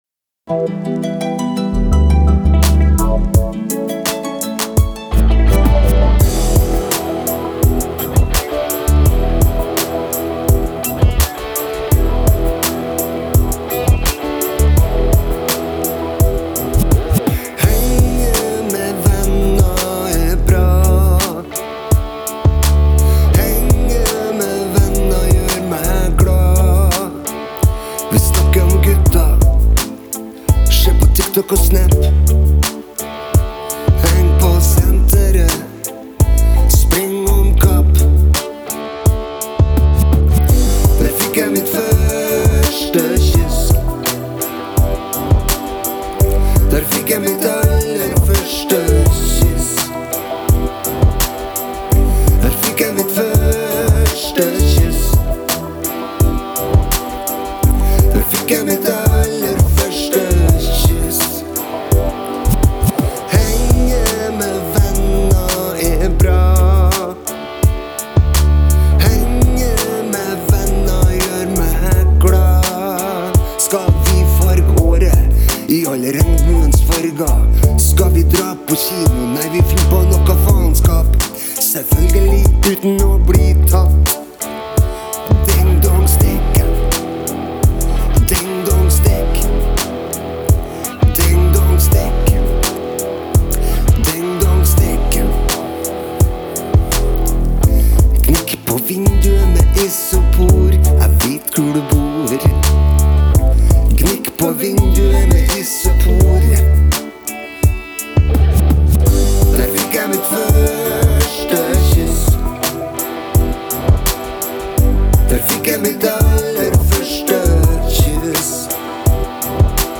alle instrumenter og programmering